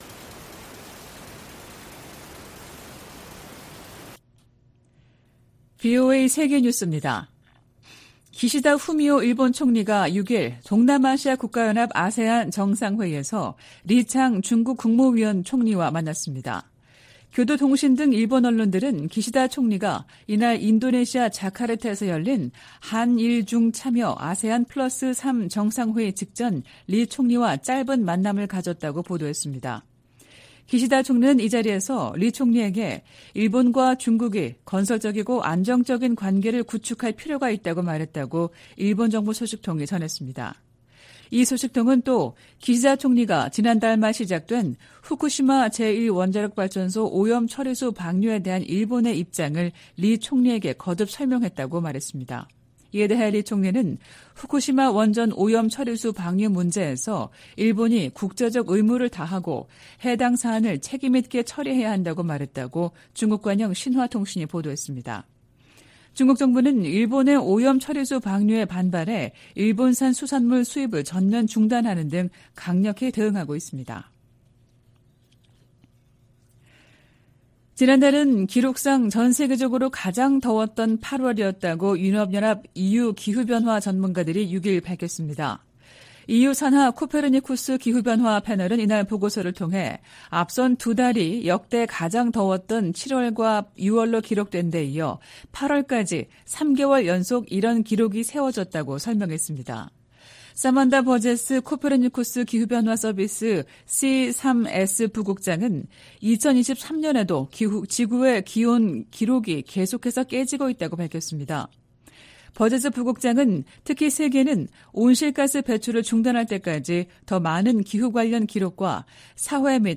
VOA 한국어 '출발 뉴스 쇼', 2023년 9월 7일 방송입니다. 백악관은 북한과 러시아가 정상 회담을 추진하고 있다는 보도가 나온 가운데 양국 간 무기 협상을 중단하라고 촉구했습니다. 윤석열 한국 대통령도 북-러 군사협력을 시도하지 말라고 요구했습니다. 김정은 북한 국무위원장과 블라디미르 푸틴 러시아 대통령은 정상회담에서 양국 군사협력을 새로운 차원으로 진전시키는 중요한 합의를 발표할 것으로 미국 전문가들이 내다봤습니다.